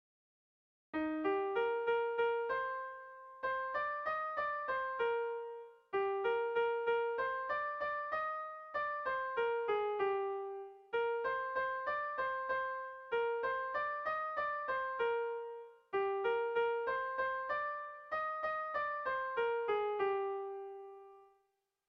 Paratu behar ditut B - Bertso melodies - BDB.
Zortziko txikia (hg) / Lau puntuko txikia (ip)
AB1DB2